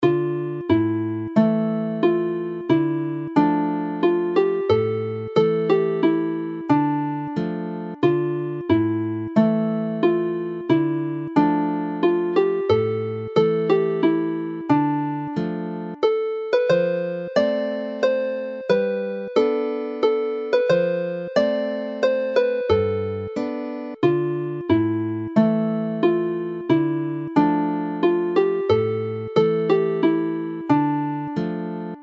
Y Gog Lwydlas is haunting minor key song in the Dorian mode.